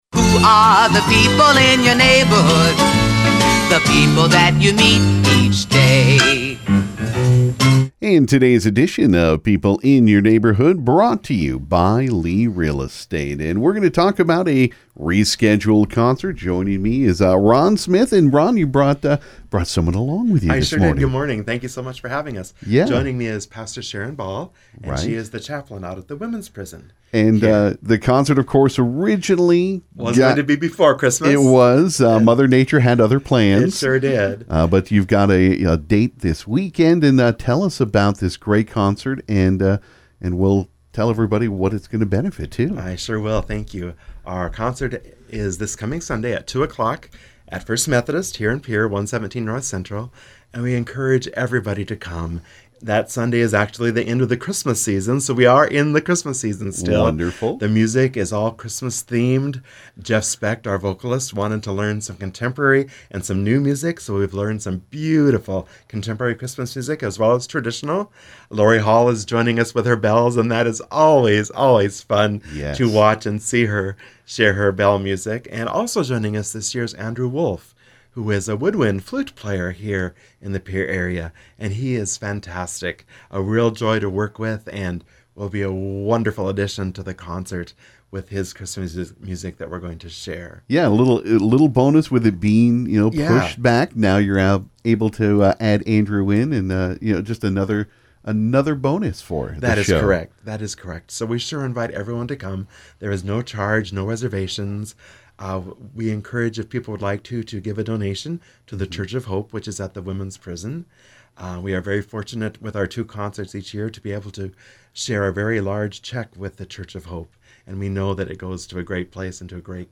This morning on KGFX